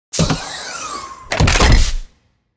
dronereturn.ogg